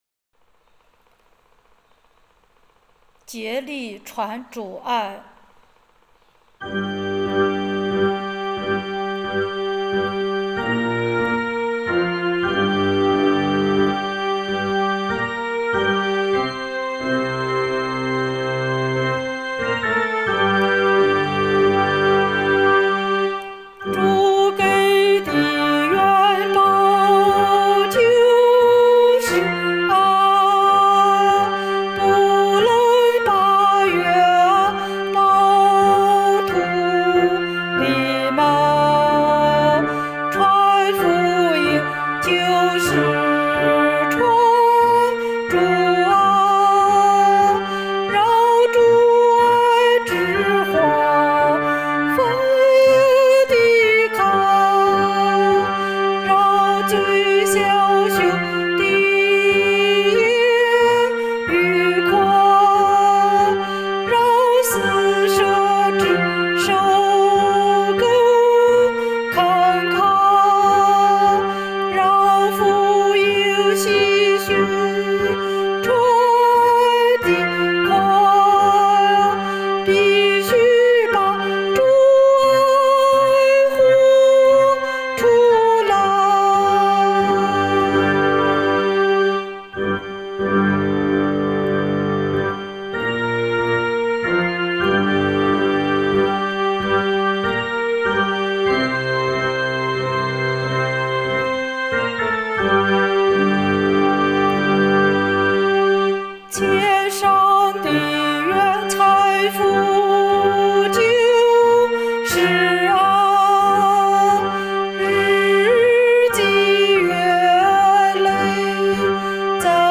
【原创圣歌】|《竭力传主爱》